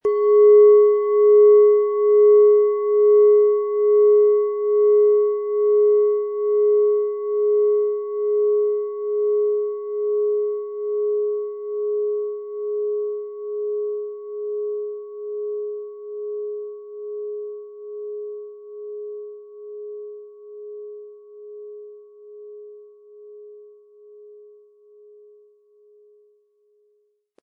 Japanische Klangschale Solfeggio 417 Hz - Veränderung
Die Klangschale mit 417 Hertz entfaltet einen klaren Ton, der Leichtigkeit schenkt und neue Impulse setzt.
Fein gearbeitete Klangschalen mit 417 Hz zeichnen sich durch einen reinen, gleichmäßigen Ton aus. Der Nachhall wirkt lebendig und klar, schafft Raum für Inspiration und stärkt die innere Ausrichtung.
MaterialBronze